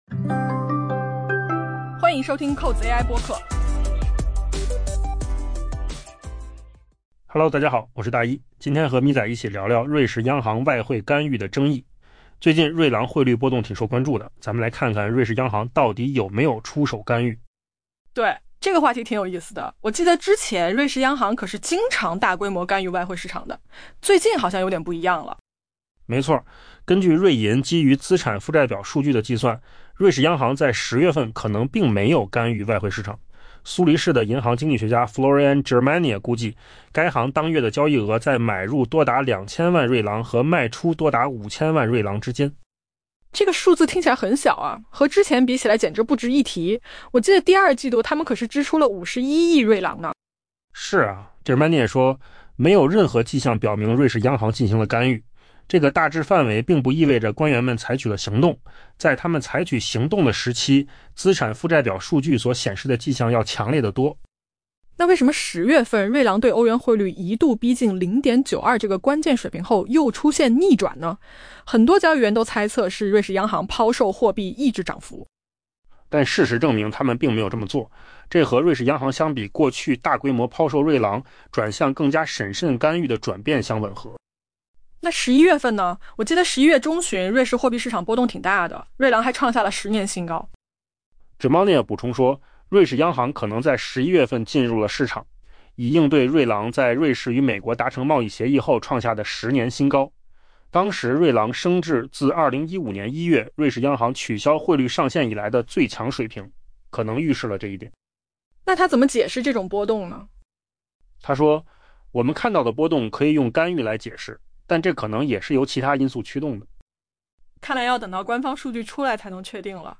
AI 播客：换个方式听新闻 下载 mp3 音频由扣子空间生成 根据瑞银 （UBS） 基于资产负债表数据的计算， 瑞士央行在 10 月份可能并没有干预外汇市场以阻止瑞郎的避险升势。